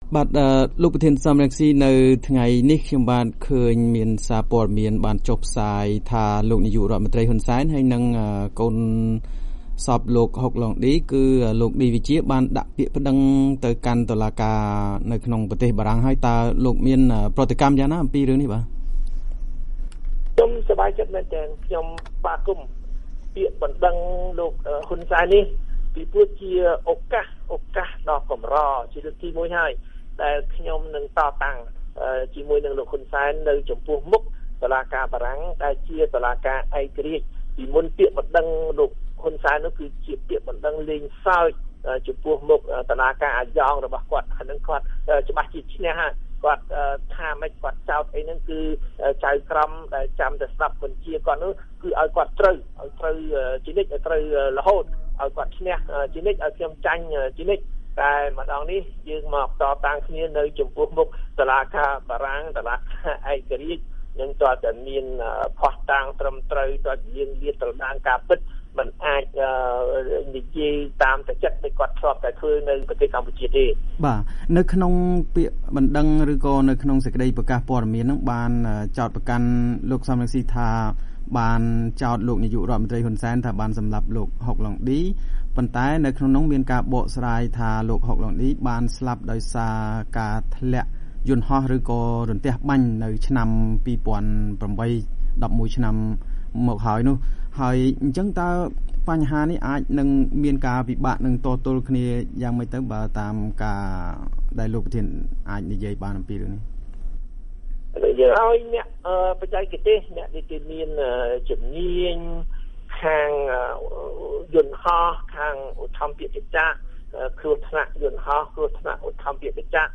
បានជួបសម្ភាសន៍ លោក សម រង្ស៊ី តាមទូរស័ព្ទពីប្រទេសអូស្ត្រាលី។